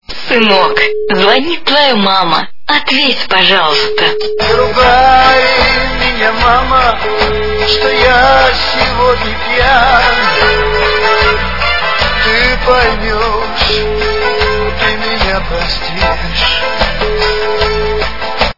» Звуки » Смешные » Звонок от мамы - Сынок возьми трубку!
При прослушивании Звонок от мамы - Сынок возьми трубку! качество понижено и присутствуют гудки.